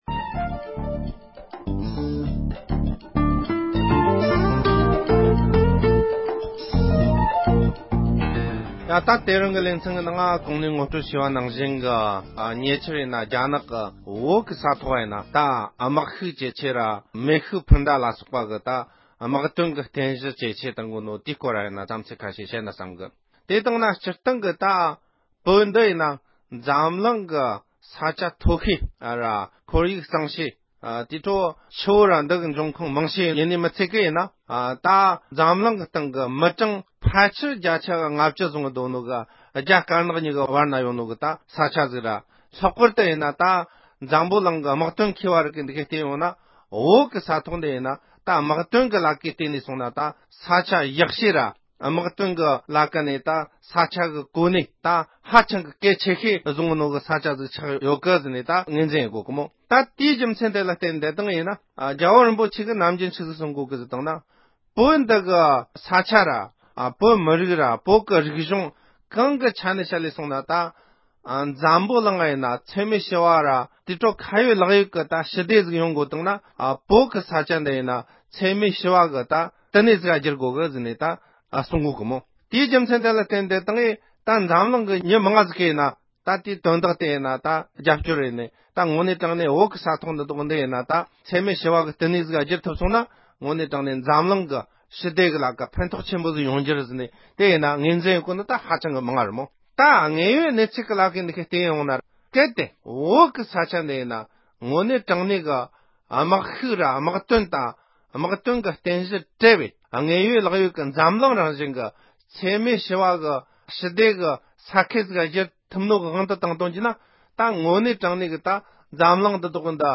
རྒྱ་ནག་གིས་བོད་ནང་དམག་དོན་རྟེན་གཞི་དང་དཔུང་ཤུགས་རྒྱ་བསྐྱེད་གཏོང་བཞིན་པའི་ཐད་དཔྱད་གཏམ།